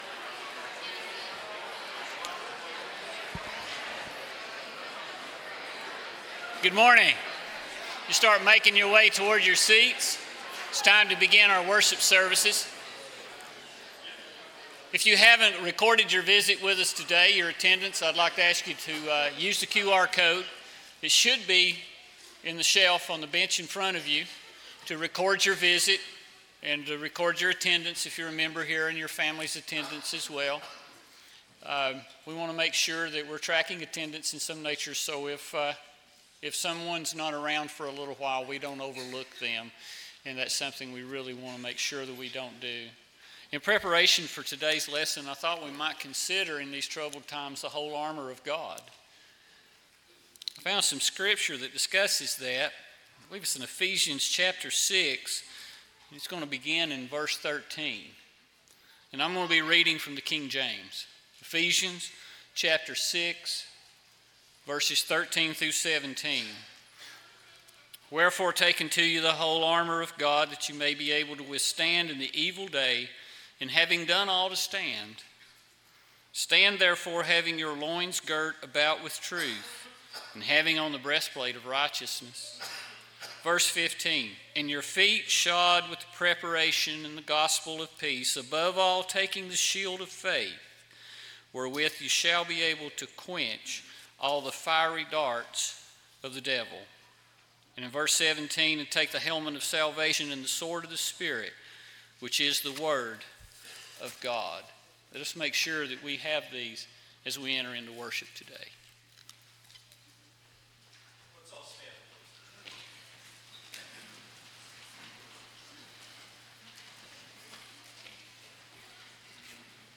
Matthew 11:28, English Standard Version Series: Sunday AM Service